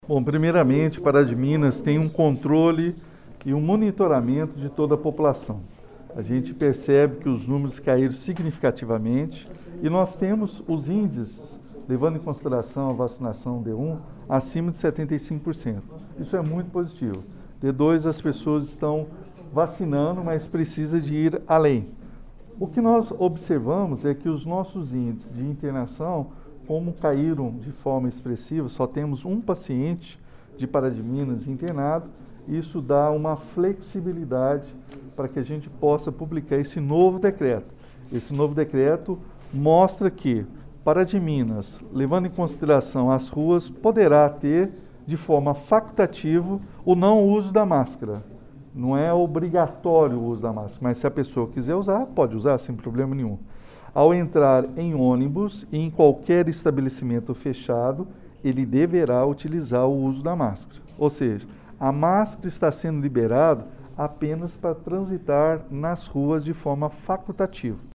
Em decisão unânime, os membros do Comitê aprovaram a desobrigação do uso de máscaras em espaços abertos em Pará de Minas, como explicou o prefeito Elias Diniz: